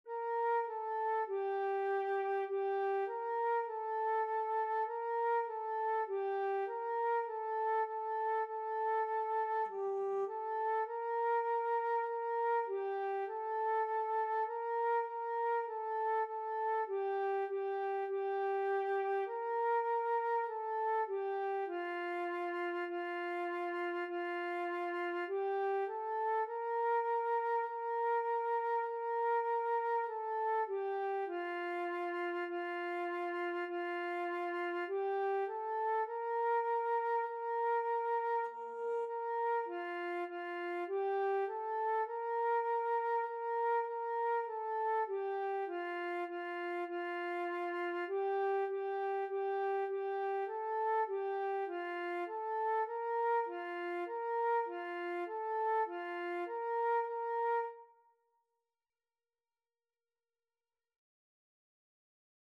4/4 (View more 4/4 Music)
F5-Bb5
Beginners Level: Recommended for Beginners
Instrument:
Flute  (View more Beginners Flute Music)
Classical (View more Classical Flute Music)